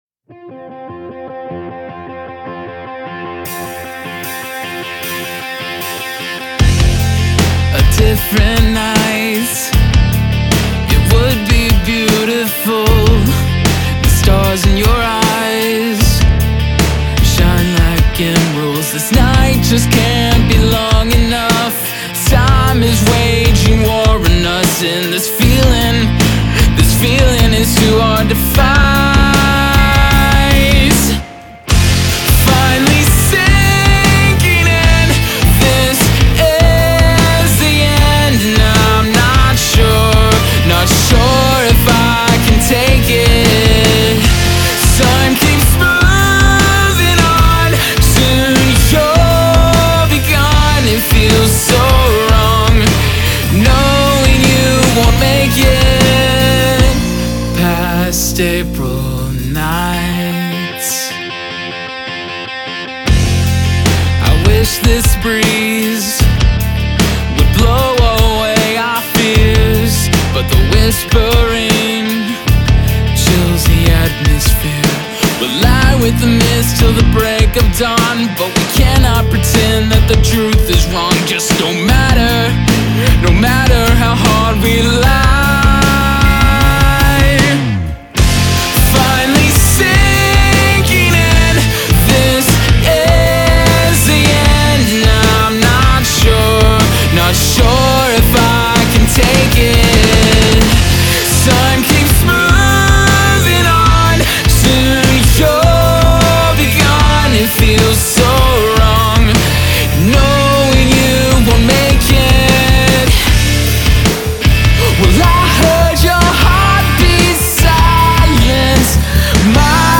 Vocals & Guitar
Bass & Vocals
Drums
We’re an emo pop rock band from Louisville, KY.